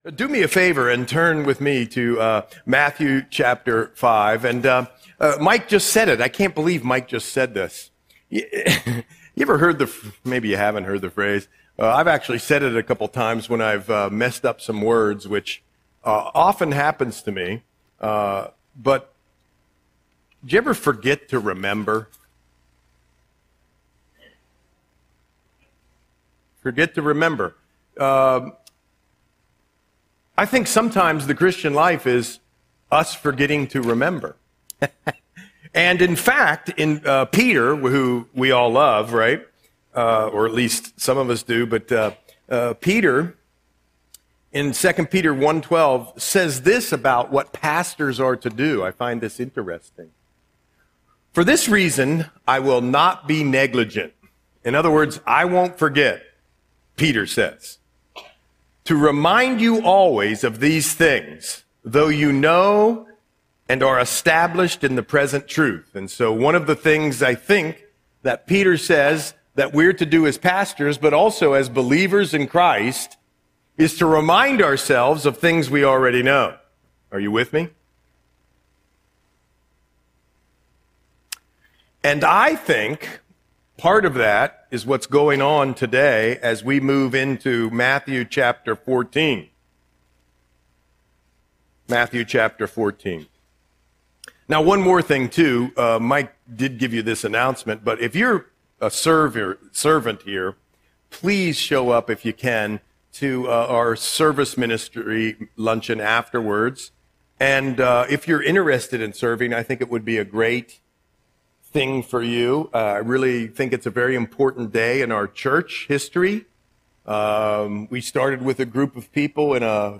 Audio Sermon - March 8, 2026